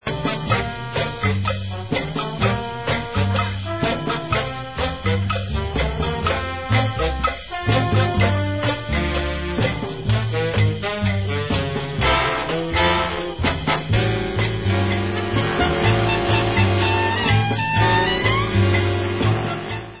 W/his orchestra (patio garden ballroom, utah 1962)
sledovat novinky v oddělení Jazz